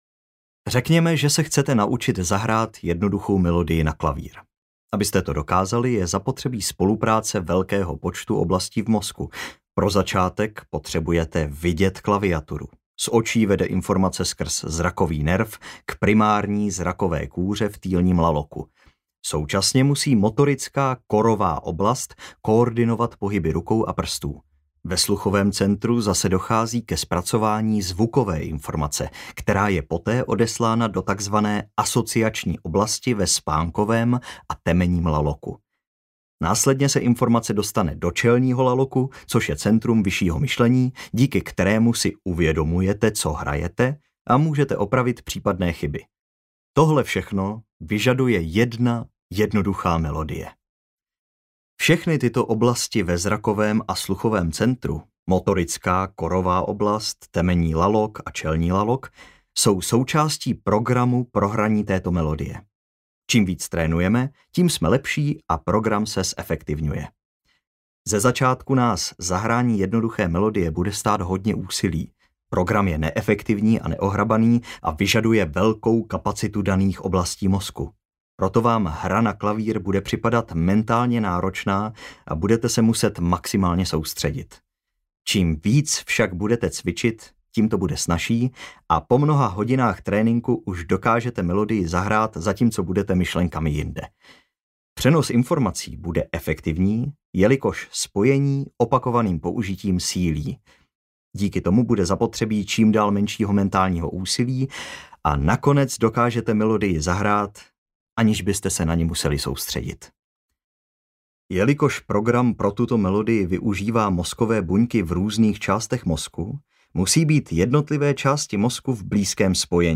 Audiokniha Doběhni svůj mozek - Anders Hansen | ProgresGuru